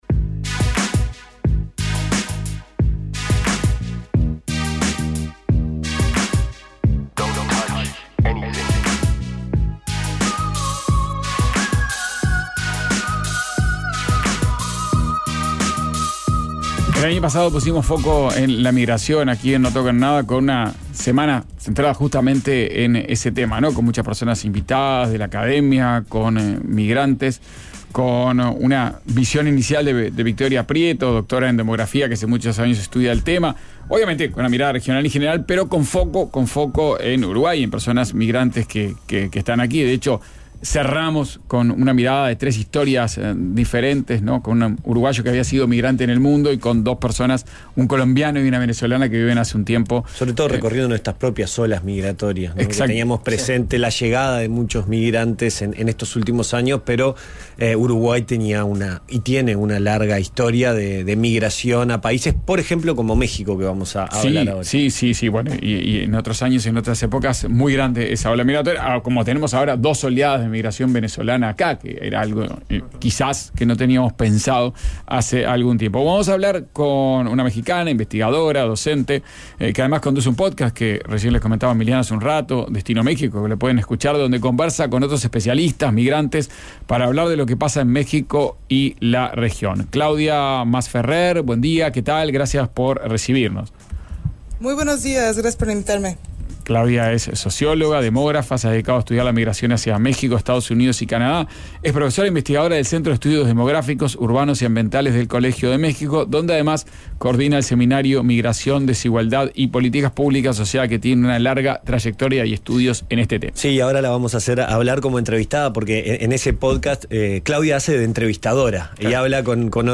Una mirada de la migración desde México y la tensión entre derechos. Entrevista